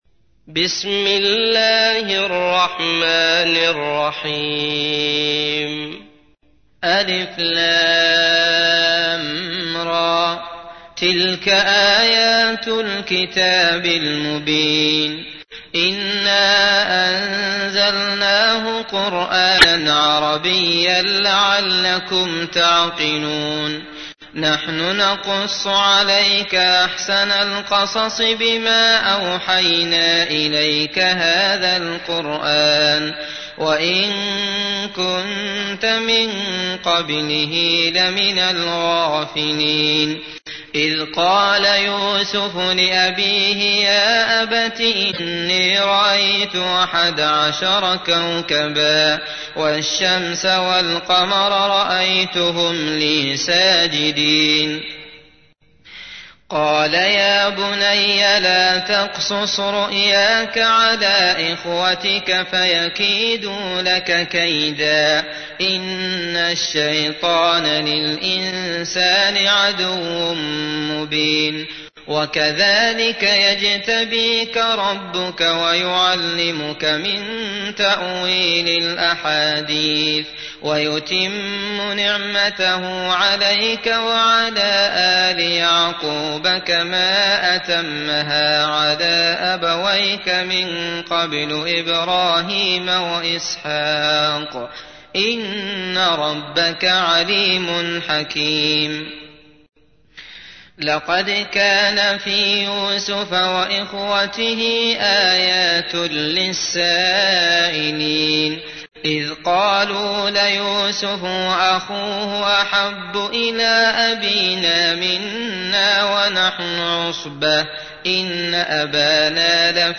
تحميل : 12. سورة يوسف / القارئ عبد الله المطرود / القرآن الكريم / موقع يا حسين